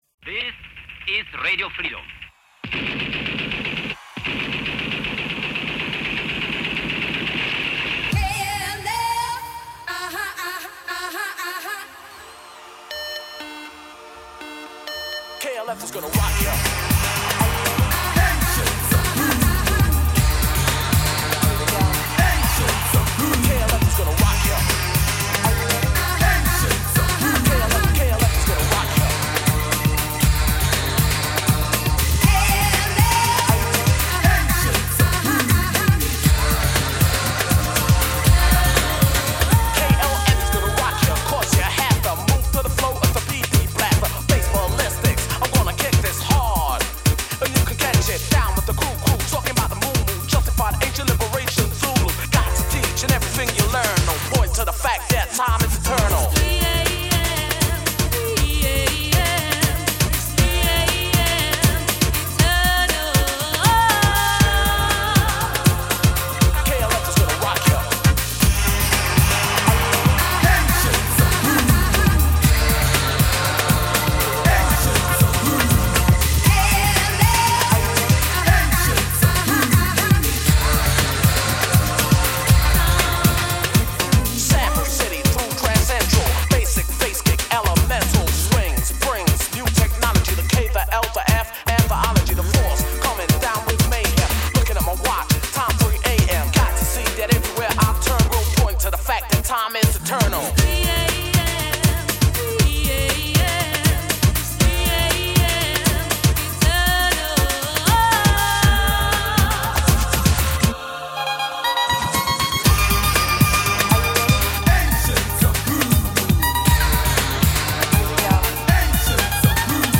Pas De Paroles